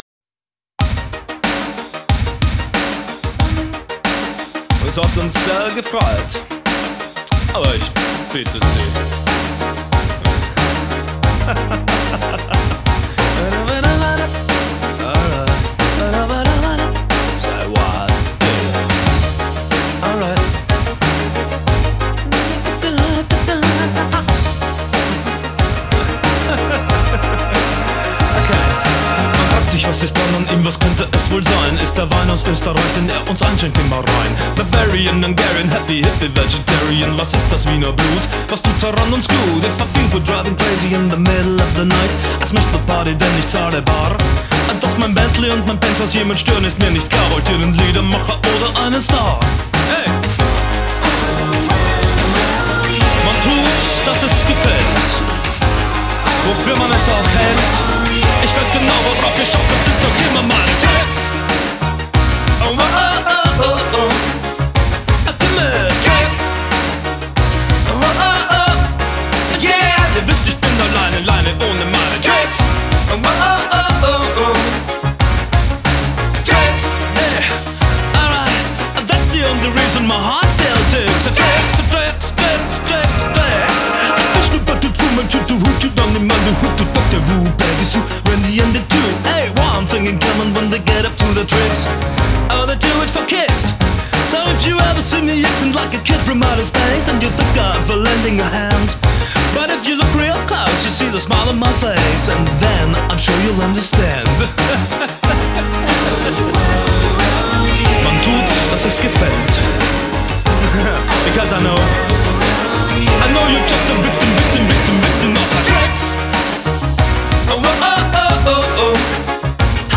Genre: Rock,New Wave